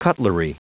Prononciation du mot cutlery en anglais (fichier audio)
Prononciation du mot : cutlery